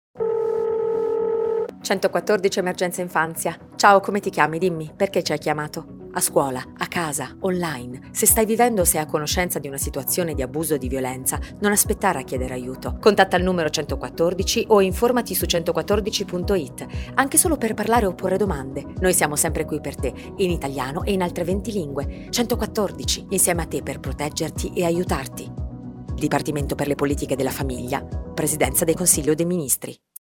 Lo spot radio
spotradio_114.mp3